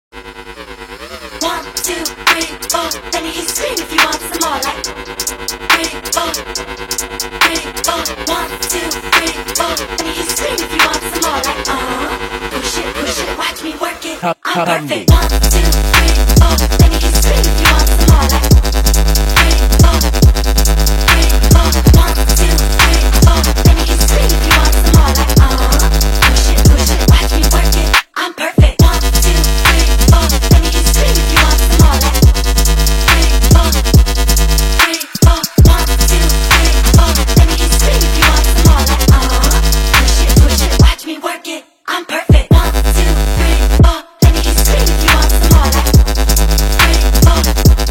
Hip Hop
a sound that was both robust and innovative